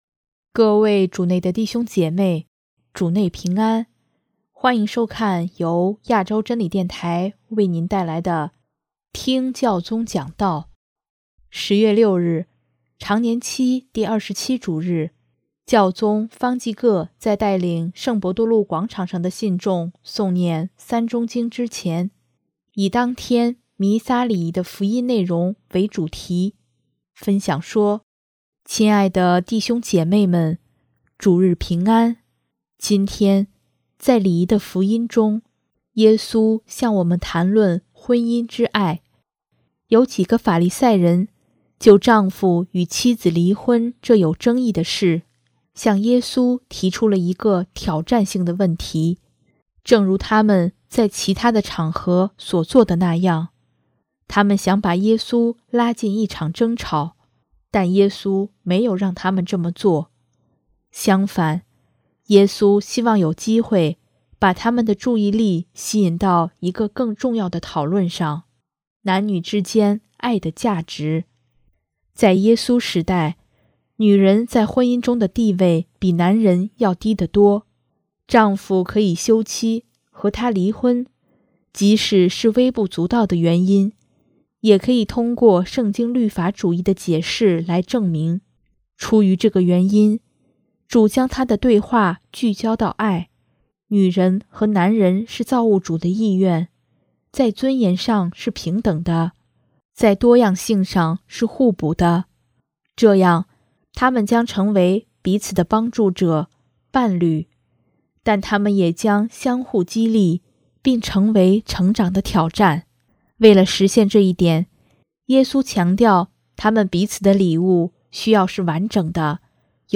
10月6日，常年期第二十七主日，教宗方济各在带领圣伯多禄广场上的信众诵念《三钟经》之前，以当天弥撒礼仪的福音内容为主题，分享说：